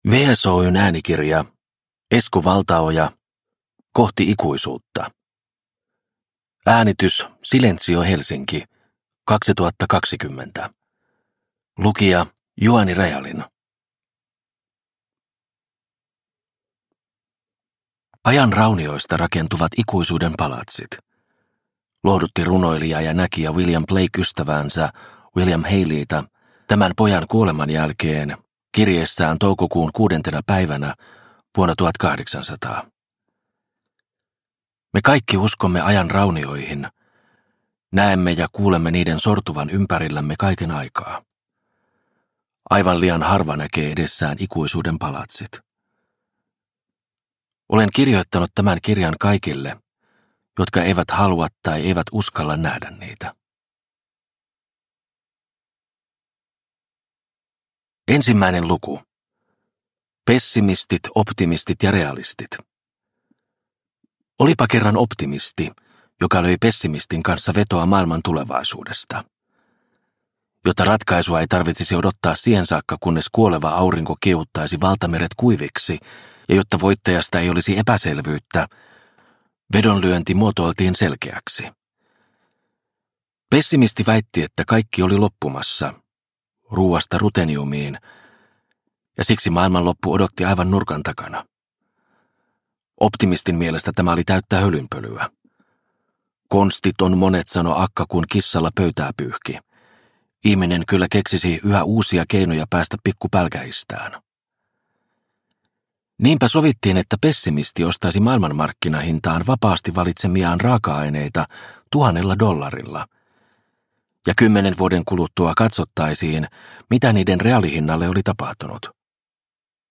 Kohti ikuisuutta – Ljudbok – Laddas ner